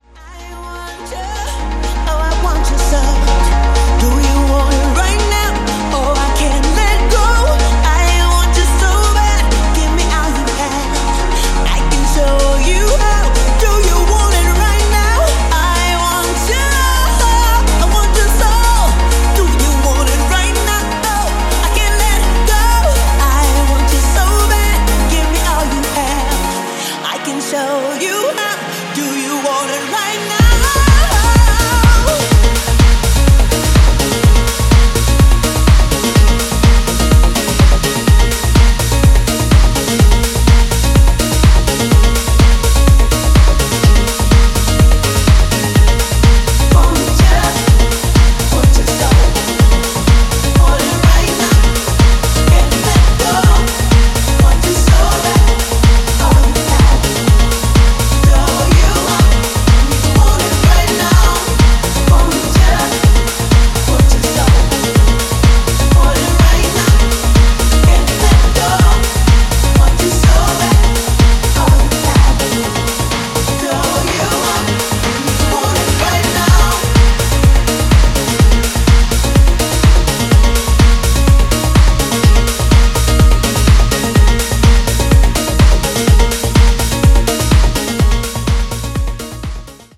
ORIGINAL EXTENDED MIX
ジャンル(スタイル) SOULFUL HOUSE / NU DISCO